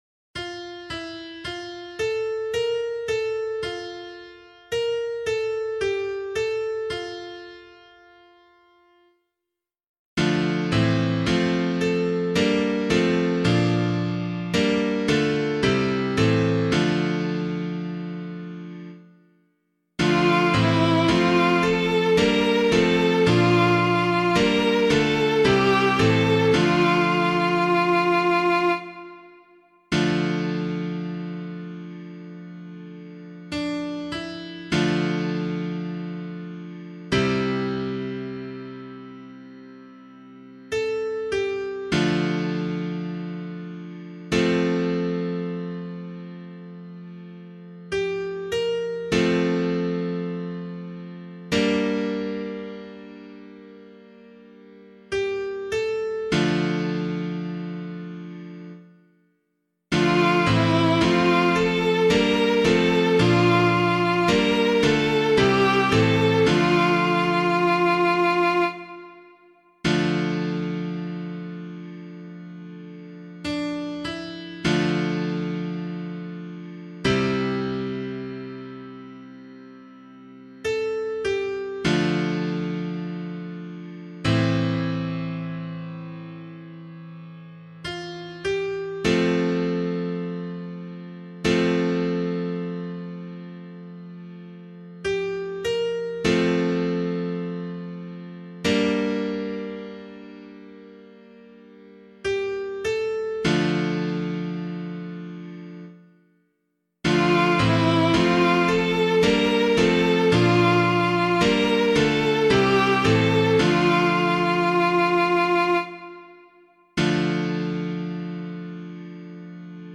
015 Lent 3 Psalm A [LiturgyShare 1 - Oz] - piano.mp3